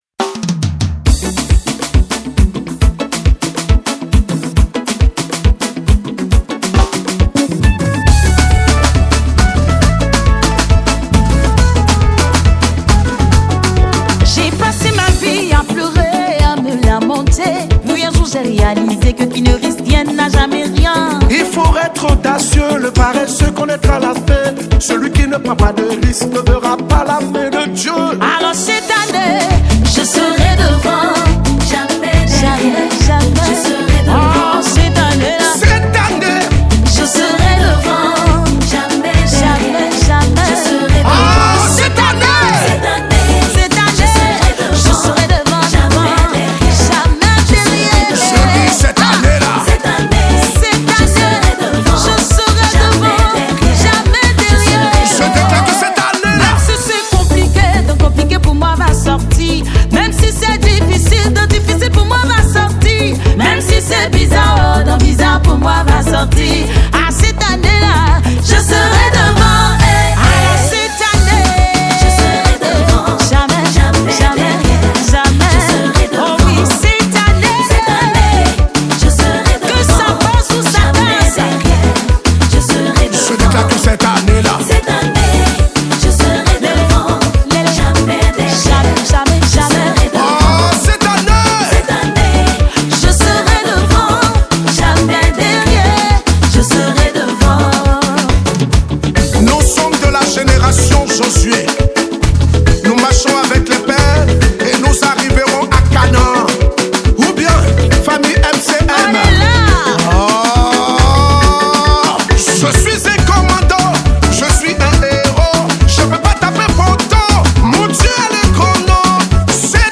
a powerful worship song to uplift and inspire you.